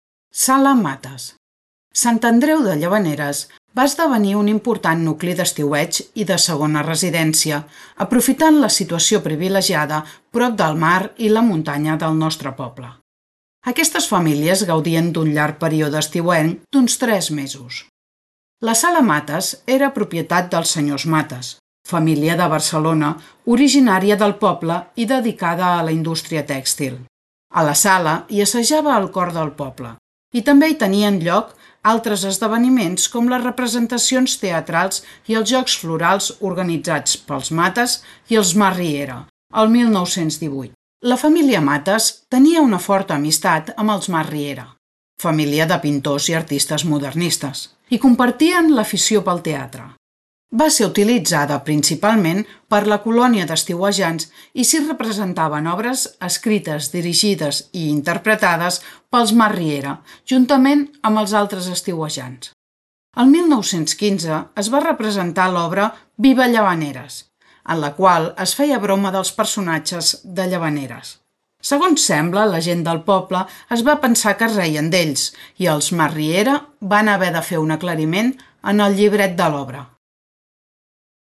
Ruta Modernista audioguiada